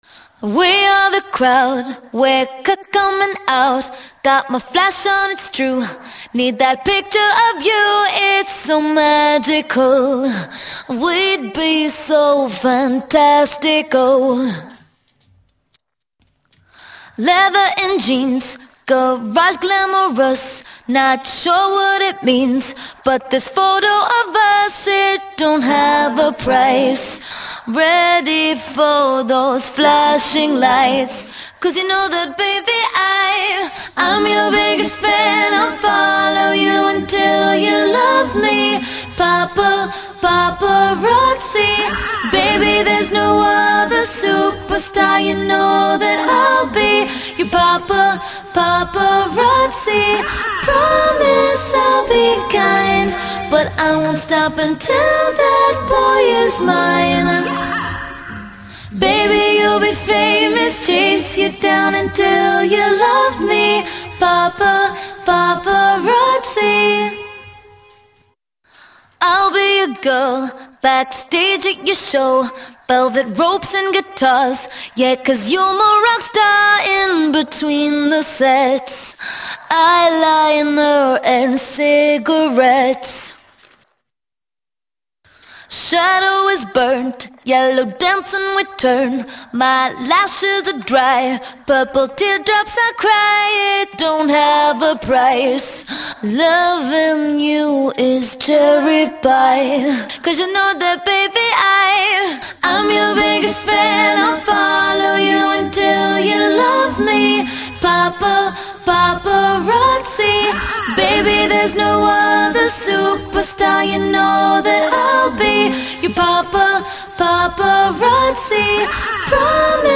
Давно искал чистую акапеллу